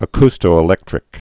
(ə-kstō-ĭ-lĕktrĭk)